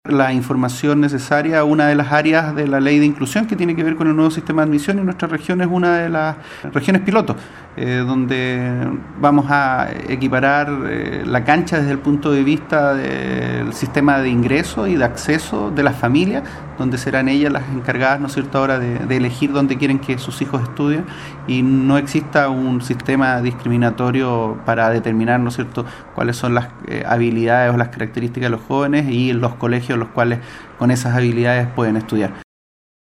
El Seremi de Educación, Pablo Baeza aprovechó esta instancia para afirmar que para esta fecha los establecimientos educaciones ya estaban a punto para recibir a los educandos en materia de infraestructura, entre otros.